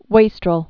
(wāstrəl)